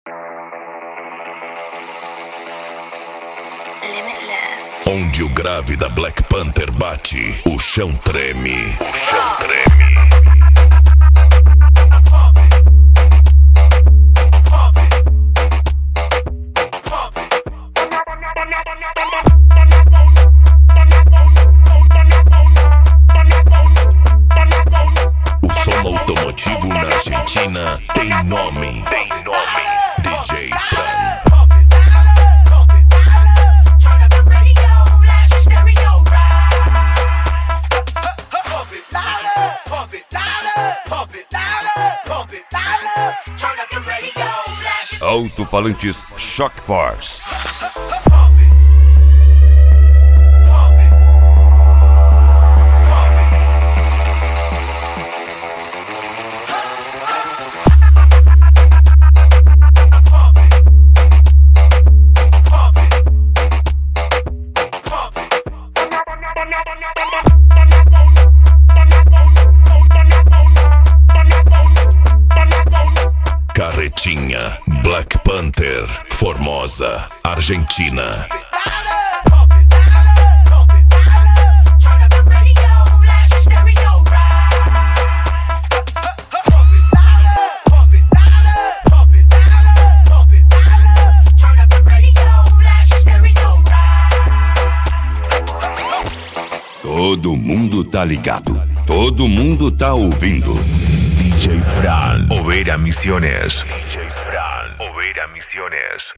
Bass
Funk
Psy Trance
Remix